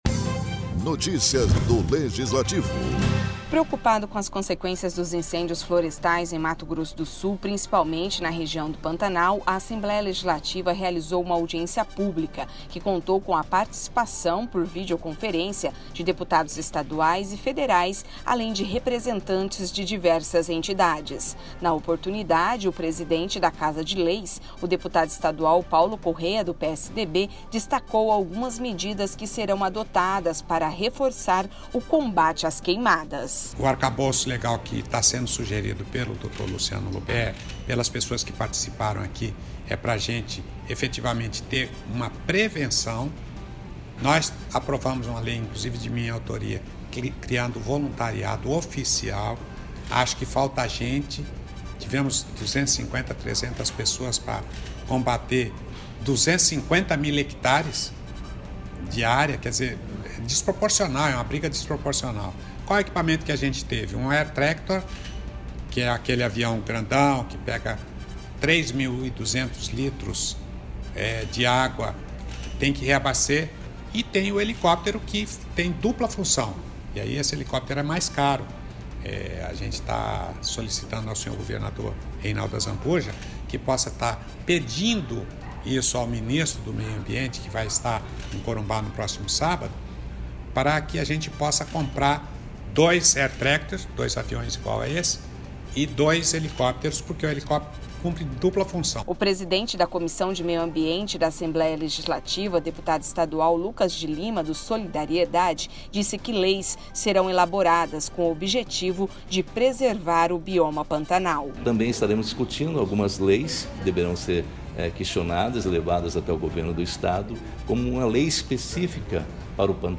Produção e Locução